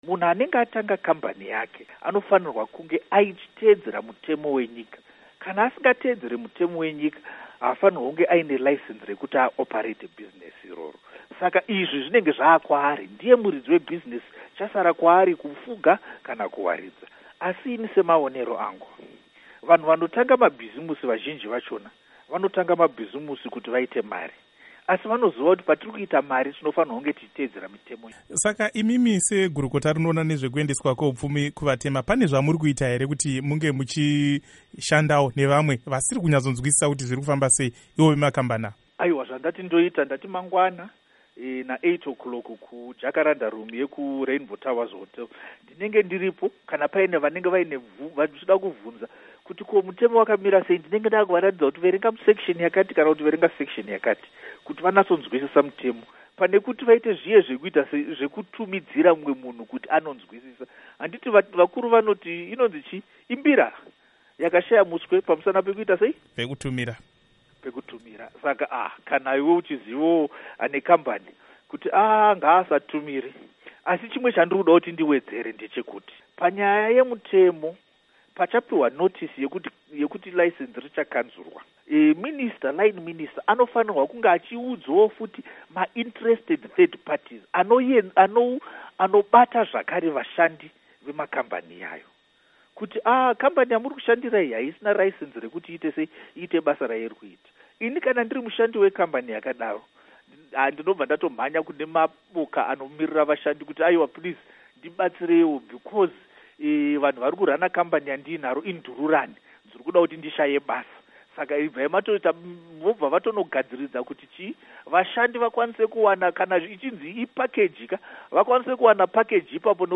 Hurukuro naVaPatrick Zhuwao